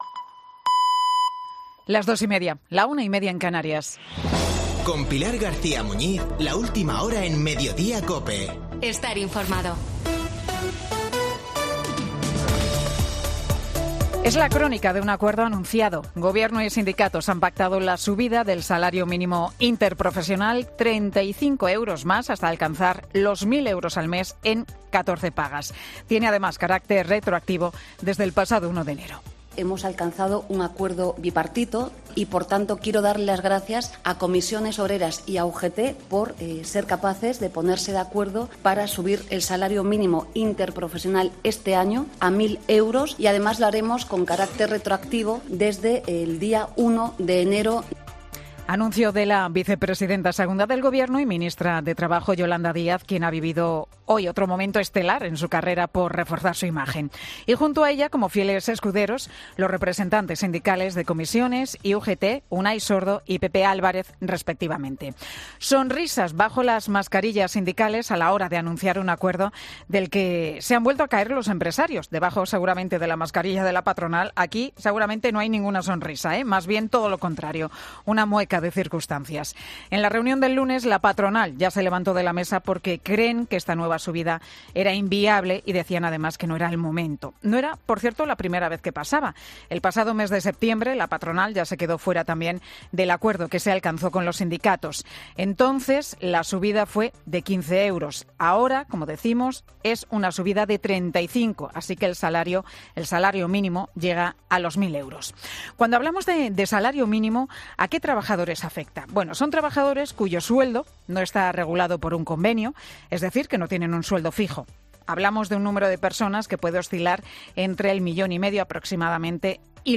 El monólogo de Pilar García Muñiz en Mediodía COPE